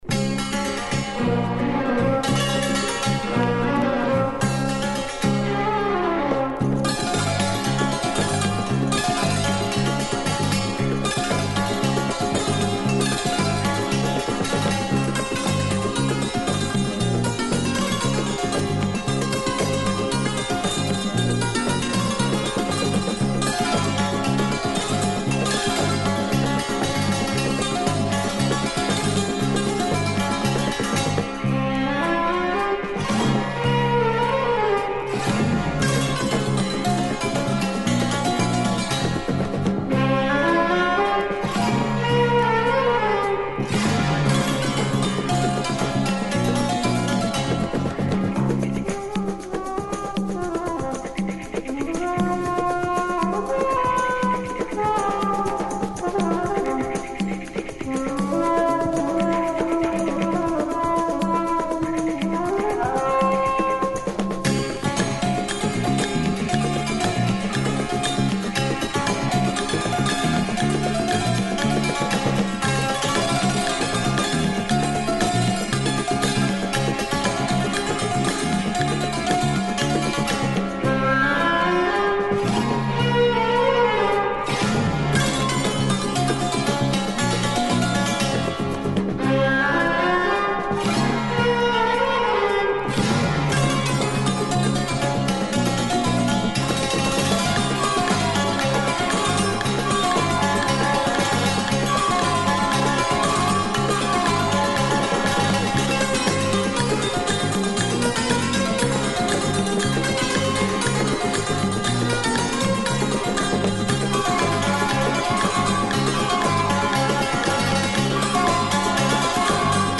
groovy oriental
with big drums